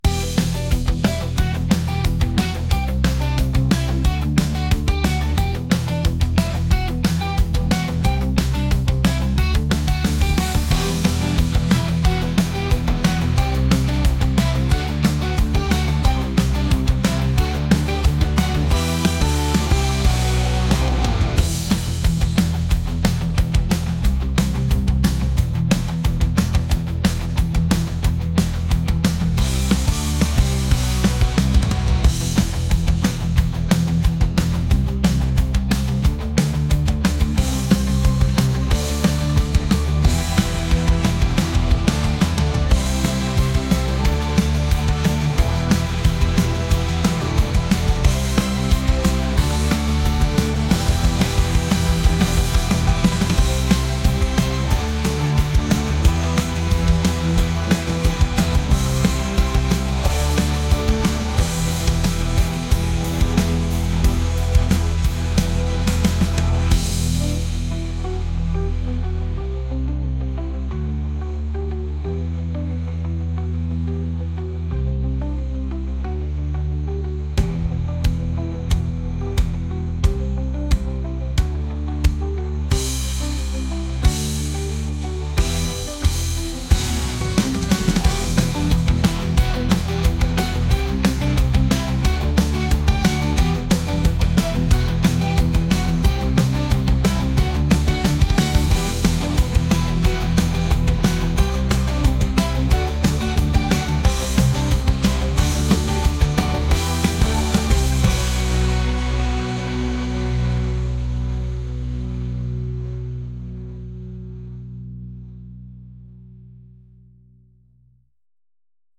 pop | indie | folk